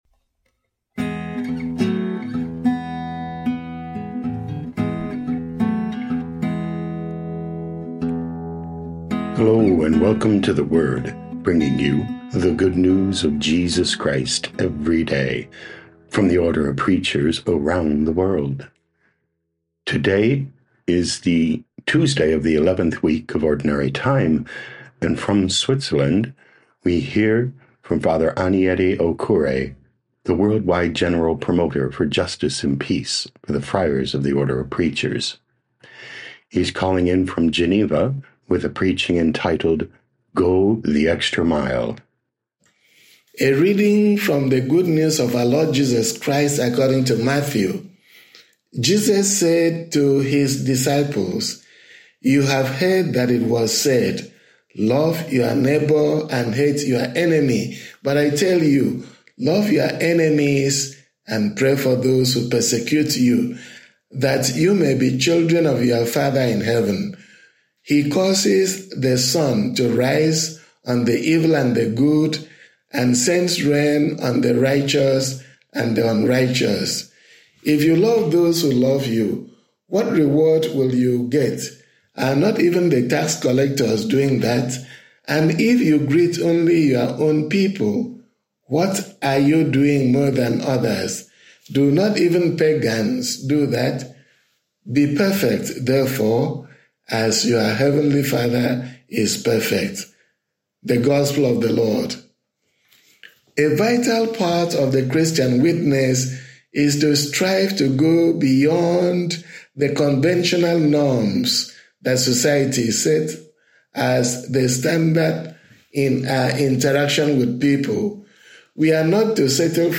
17 Jun 2025 Go the Extra Mile Podcast: Play in new window | Download For 17 June 2025, Tuesday of week 11 in Ordinary Time, based on Matthew 5:43-48, sent in from Geneva, Switzerland.
Preaching